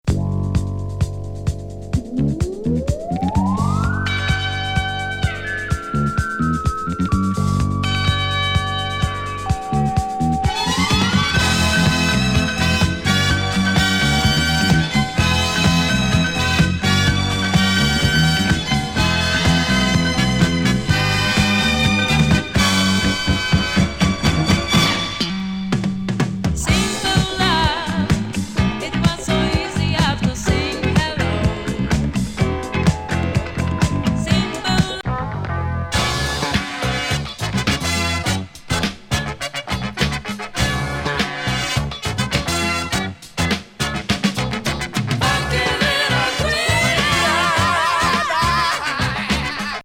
英語版ロング・バージョン！